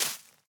Minecraft Version Minecraft Version latest Latest Release | Latest Snapshot latest / assets / minecraft / sounds / block / azalea_leaves / break3.ogg Compare With Compare With Latest Release | Latest Snapshot